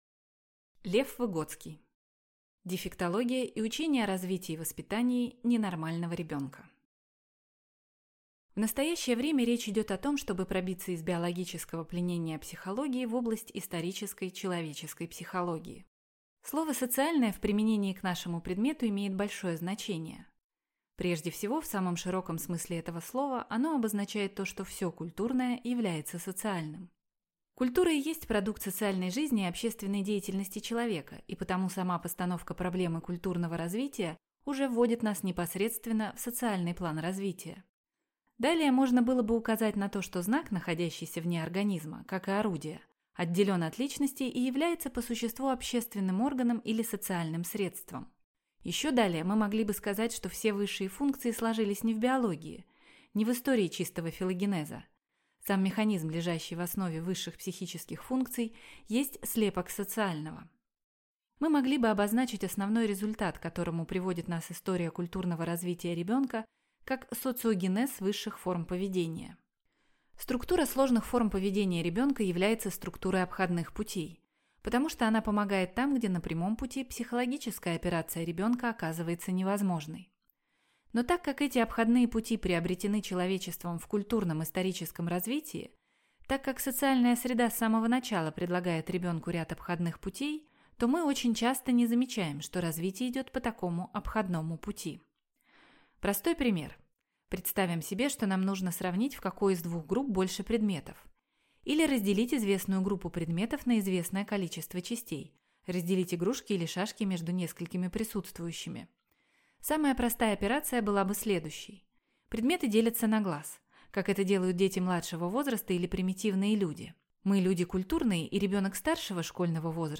Аудиокнига Дефектология и учение о развитии и воспитании ненормального ребенка | Библиотека аудиокниг